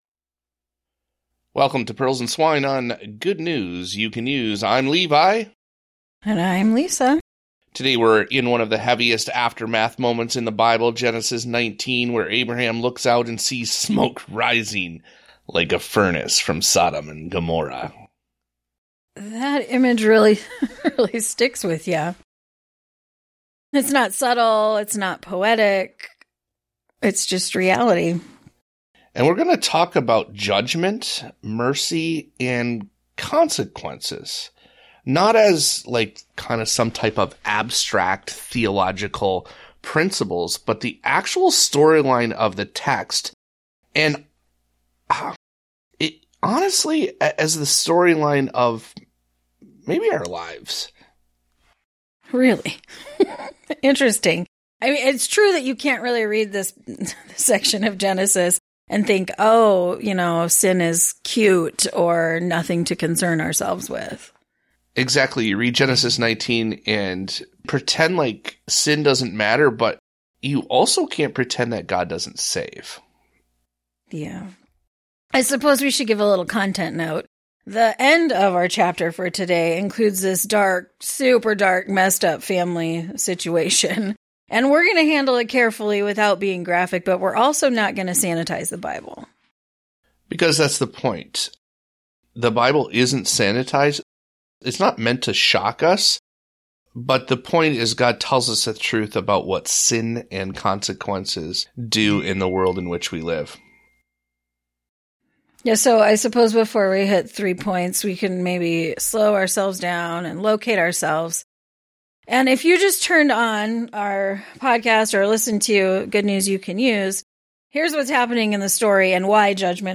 What is a Christian response to the novel coronavirus pandemic?We take a break from our Lifeful Living sermon series to have a panel discussion, which was broadcast live for virtual congregation.